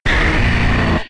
Cri de Dracaufeu K.O. dans Pokémon X et Y.